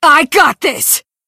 Ryuko Ryuko is Determined AF! From Kill La Kill.